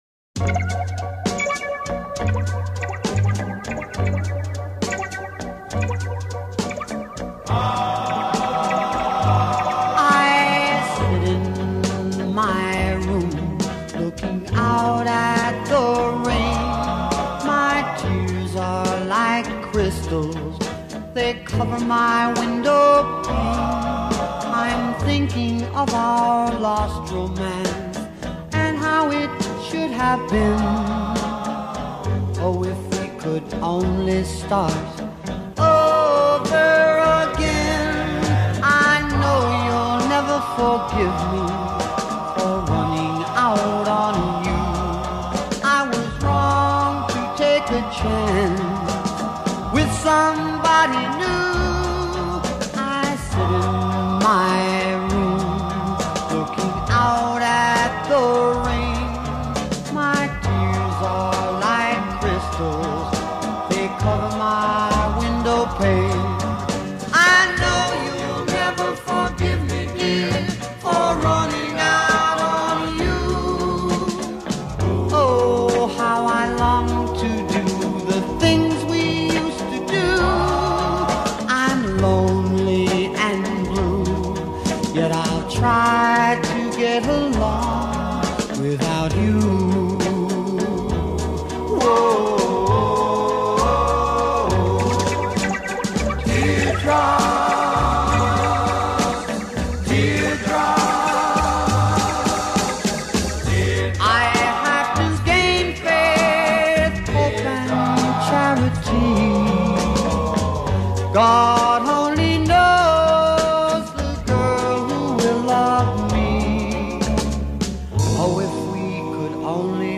Rock 'N Roll